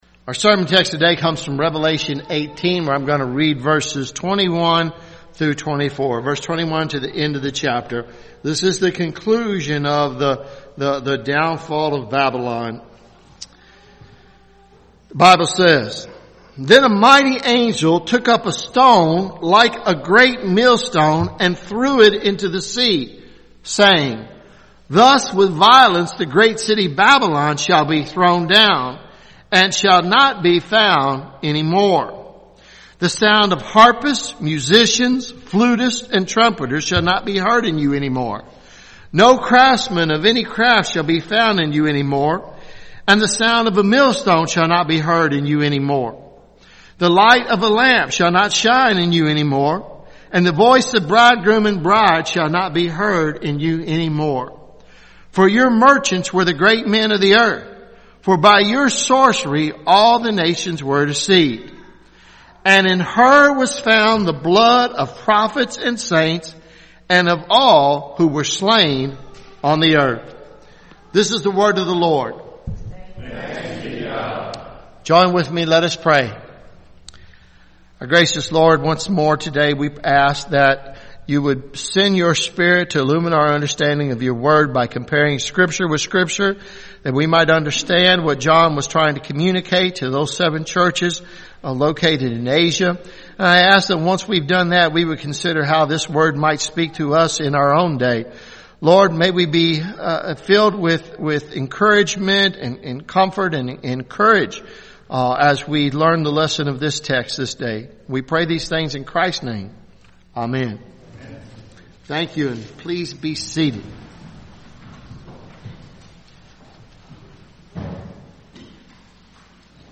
Revelation sermon series